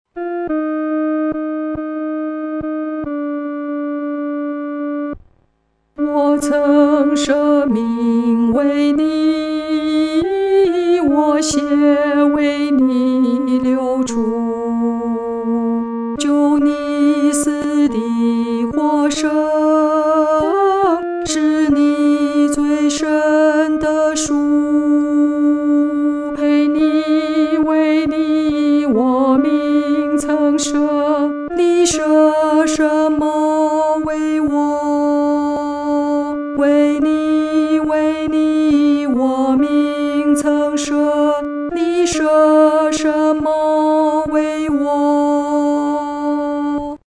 独唱（第二声）
我曾舍命为你-独唱（第二声）.mp3